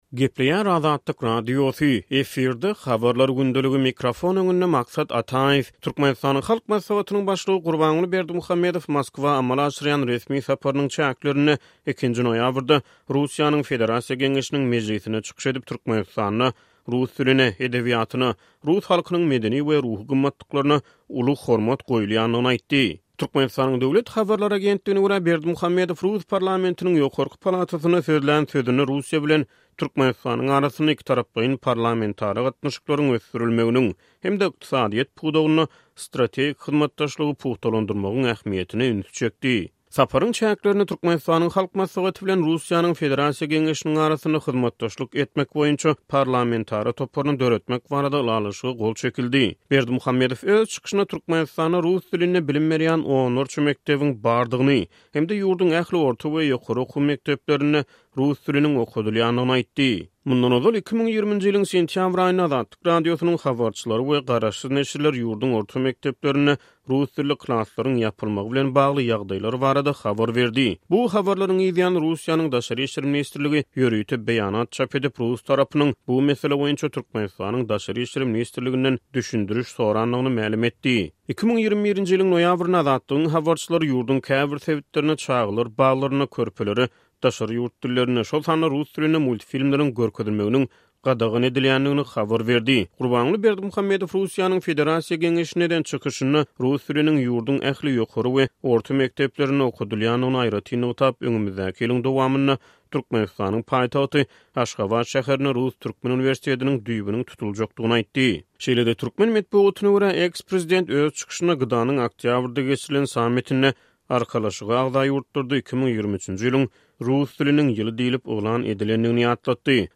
Türkmenistanyň Halk Maslahatynyň başlygy Gurbanguly Berdimuhamedow Moskwa amala aşyrýan resmi saparynyň çäklerinde, 2-nji noýabrda Russiýanyň Federasiýa Geňeşiniň mejlisinde çykyş edip, Türkmenistanda rus diline, edebiýatyna, rus halkynyň medeni we ruhy gymmatlyklaryna uly hormat goýulýandygyny aýtdy.